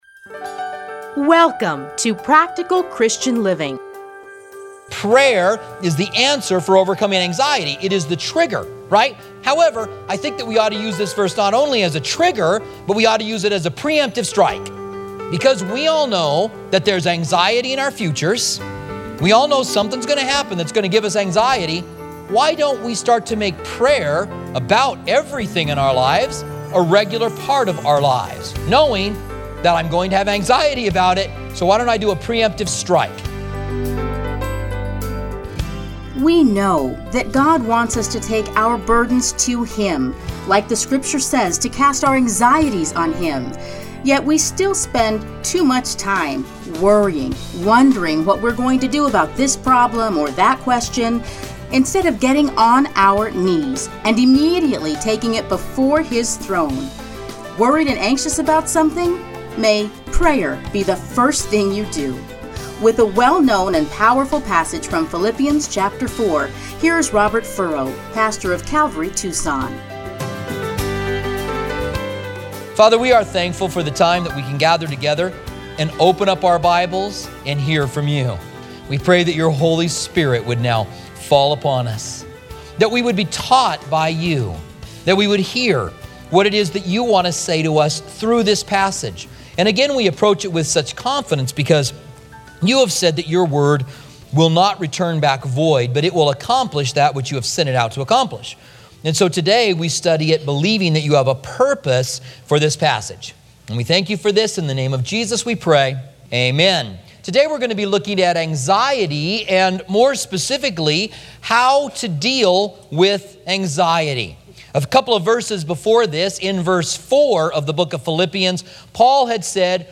30-minute radio programs